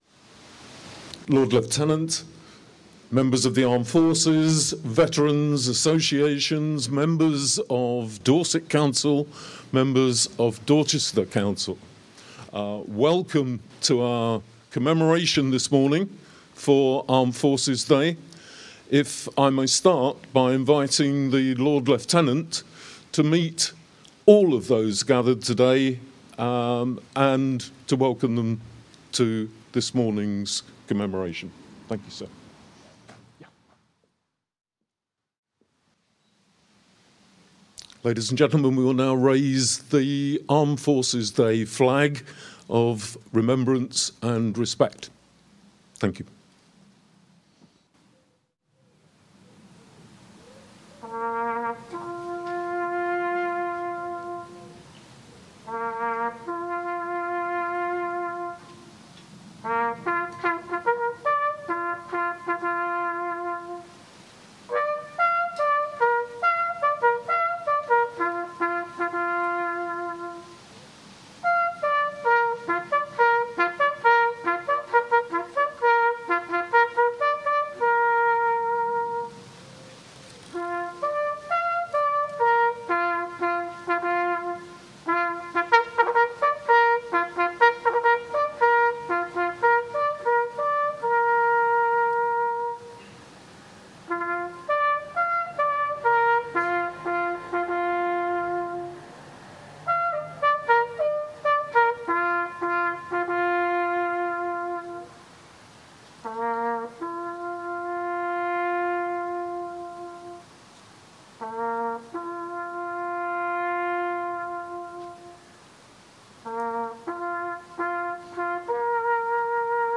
Ahead of the national Armed Forces Day on 28th June, a ceremony was held at the Memorial Area outside County Hall in Dorchester on Monday 23rd June. Attending the event were Royal British Legion Standard Bearers, military personnel, including representatives from the Royal Navy, Royal Marines, The Army and the Royal Air Force who all gave speeches. The Chair of Dorset Council, Cllr Stella Jones, was first to address the assembled personnel and public, followed by the Lord Lieutenant of Dorset, Mr Michael Dooley.